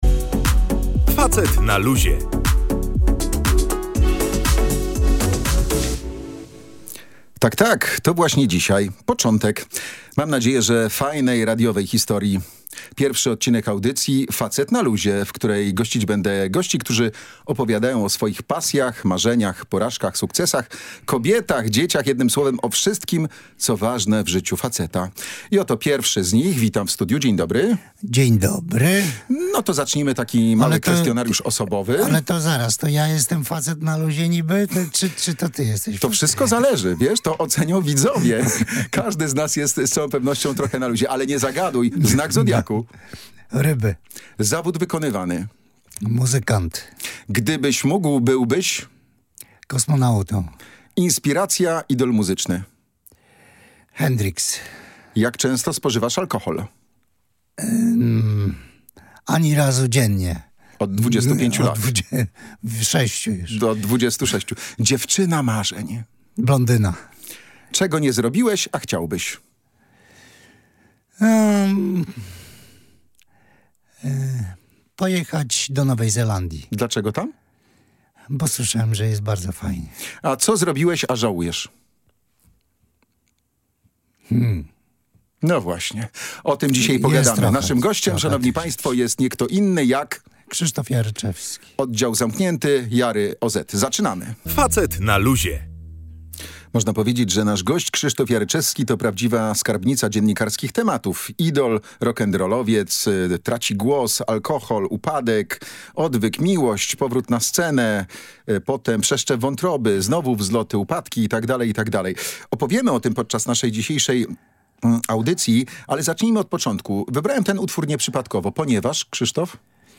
Rozmowa z Krzysztofem Jaryczewskim w audycji „Facet na luzie”